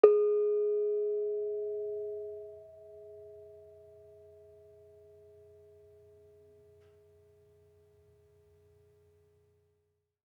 Bonang-G#3-f.wav